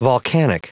Prononciation du mot volcanic en anglais (fichier audio)
Prononciation du mot : volcanic